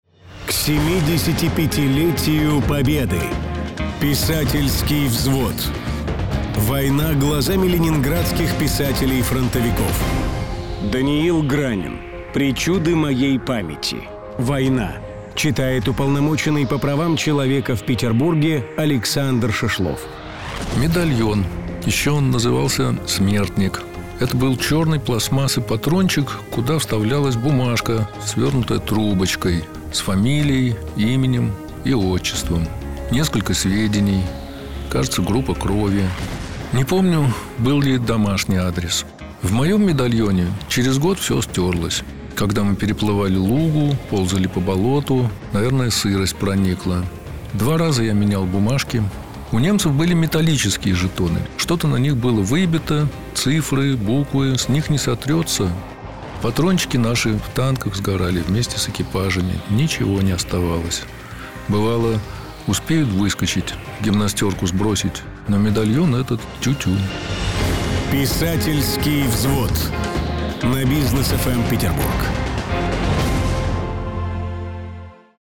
Отрывок из произведения читает уполномоченный по правам человека в Петербурге Александр Шишлов